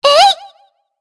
Shea-Vox_Attack2_jp.wav